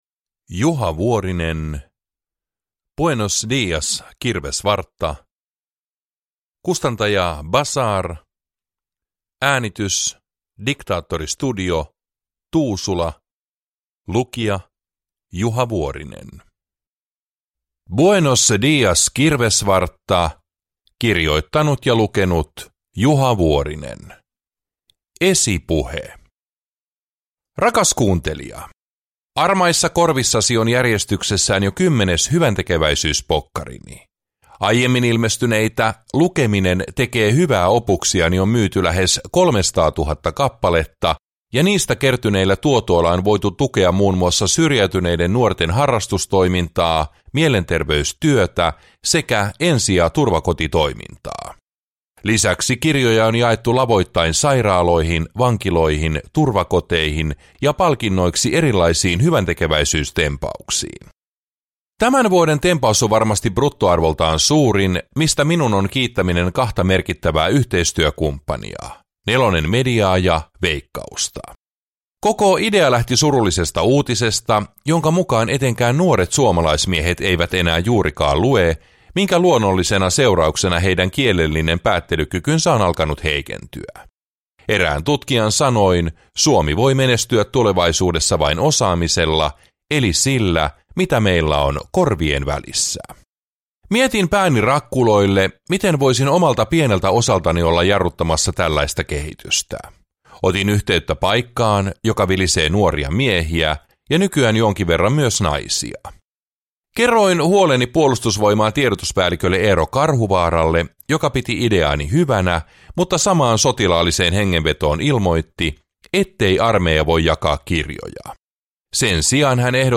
Buenos días kirvesvartta – Ljudbok
Uppläsare: Juha Vuorinen